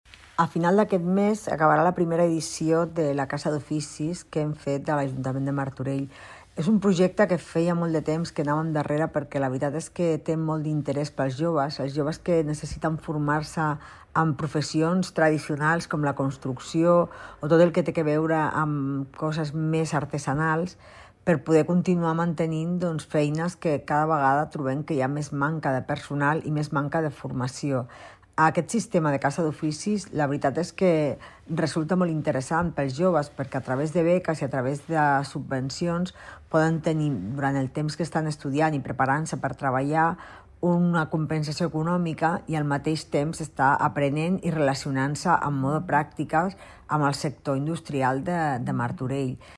Rosa Cadenas, regidora de Promoció Econòmica de l'Ajuntament